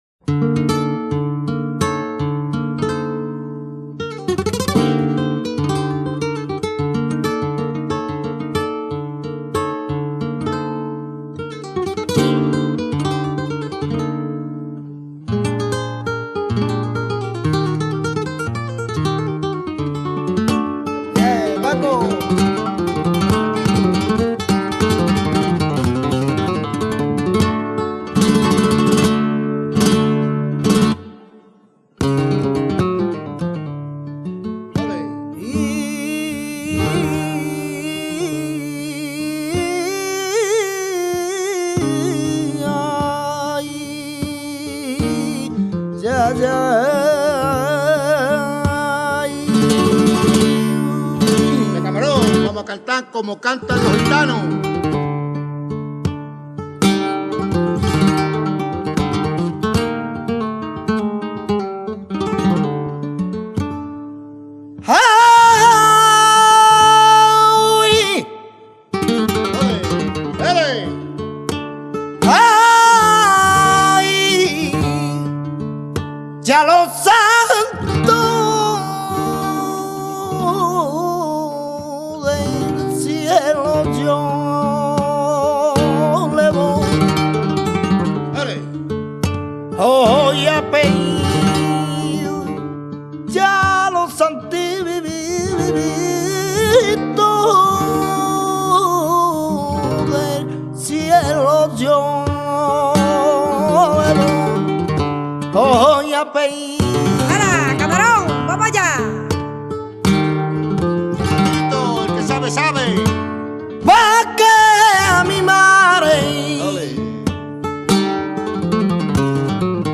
Siguiriyas